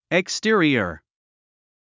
発音 ekstí ə riə r エ クステリア